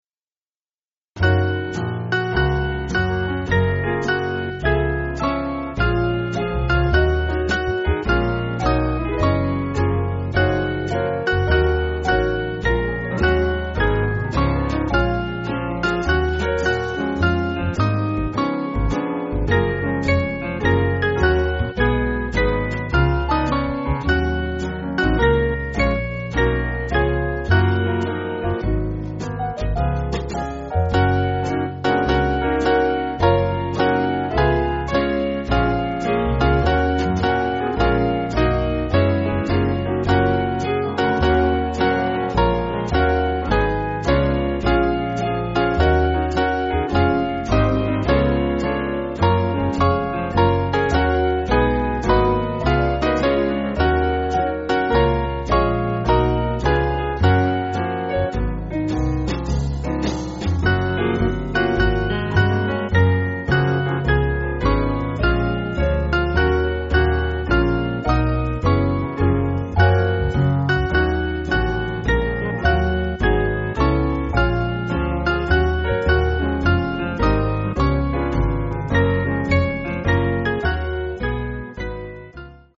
Swing Band
(CM)   5/Gb-G